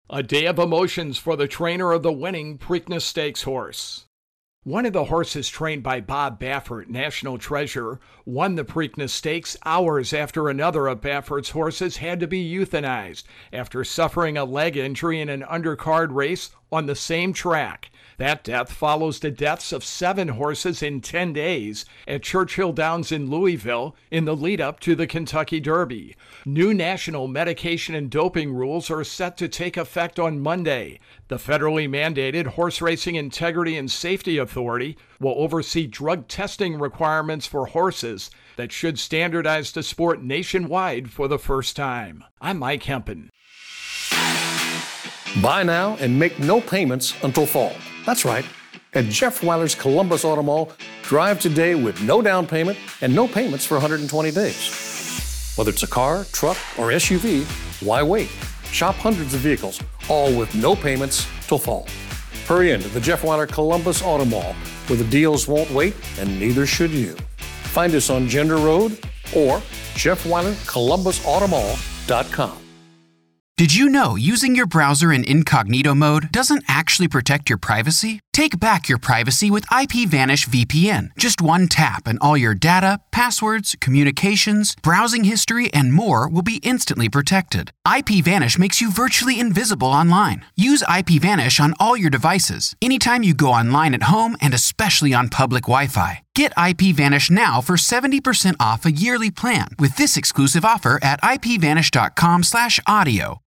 reports on the Preakness.